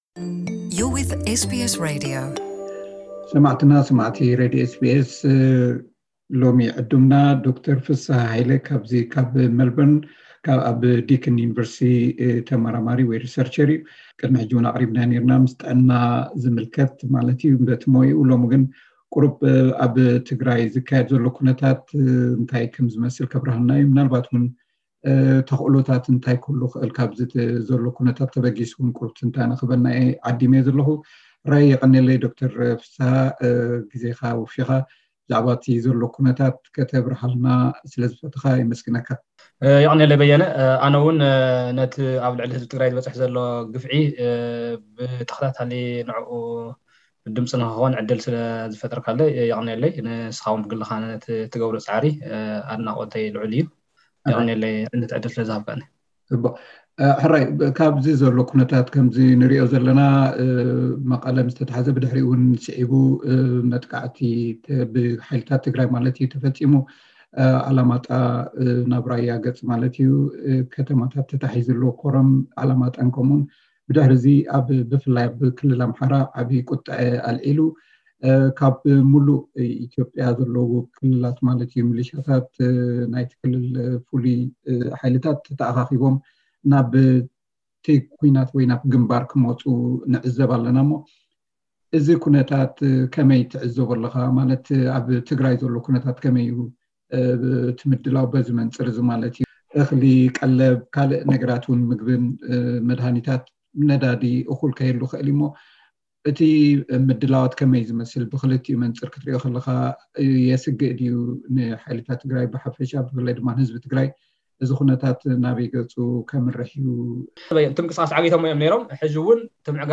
ጻንሒት